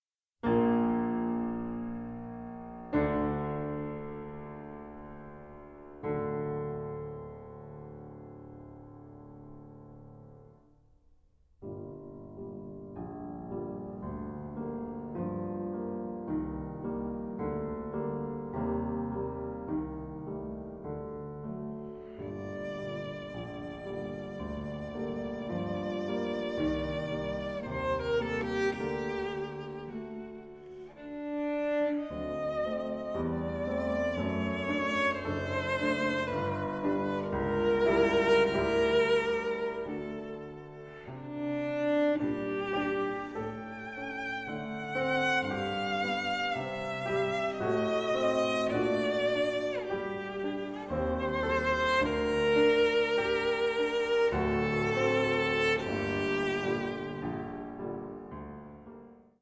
Stereo
for cello and piano